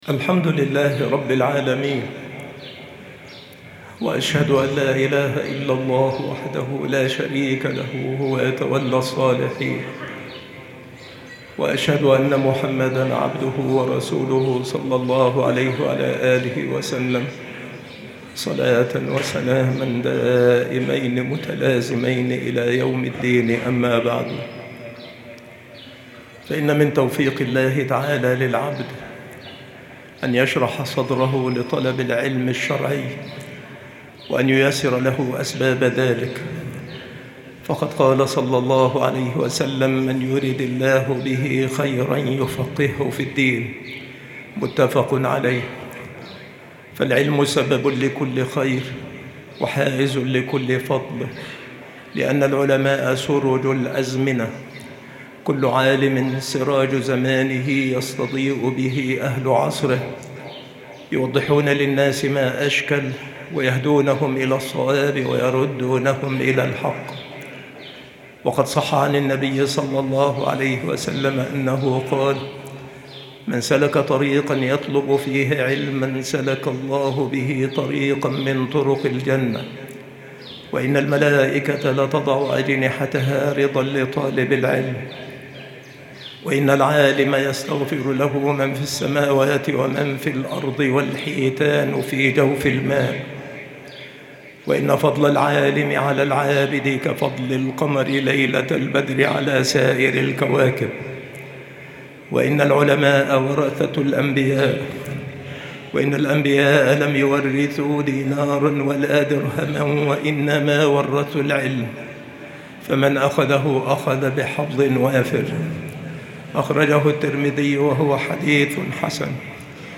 مكان إلقاء هذه المحاضرة بالمسجد الشرقي